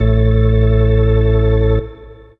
54_29_organ-A.wav